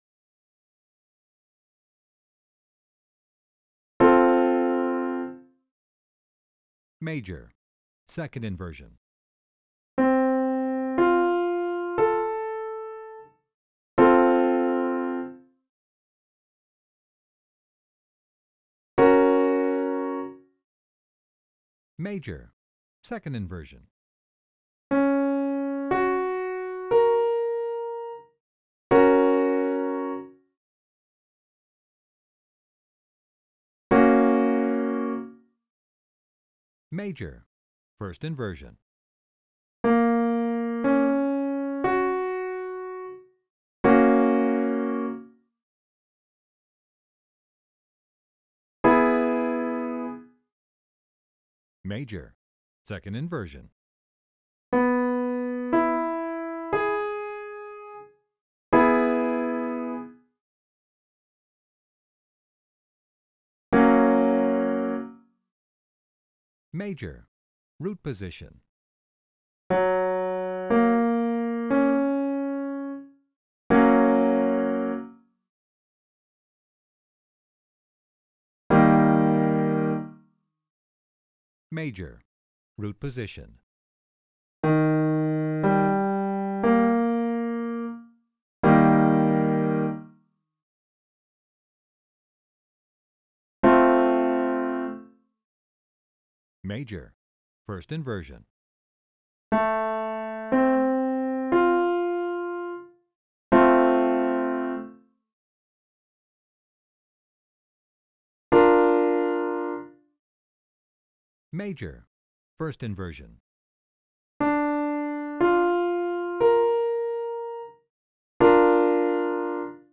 Each time a chord is played, it is then announced so you know which types of chord you’re hearing.
Training_Exercise_3._Major_triad_inversions_all_inversions.mp3